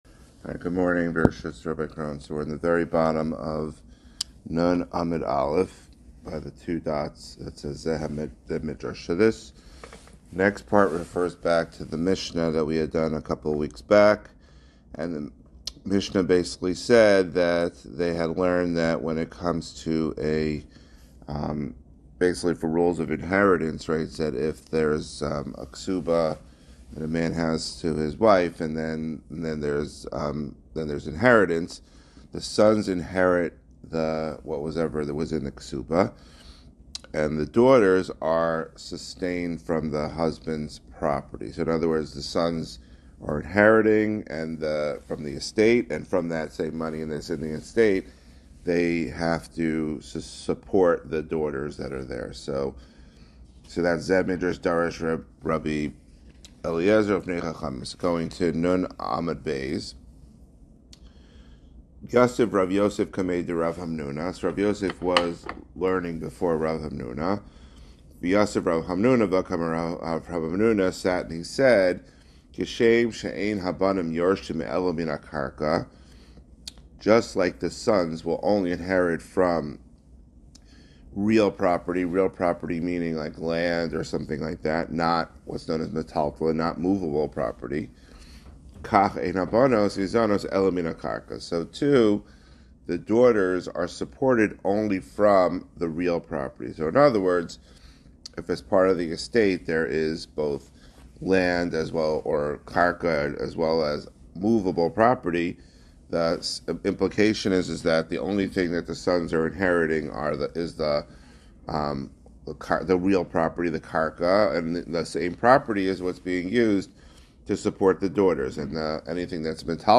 Guest shiur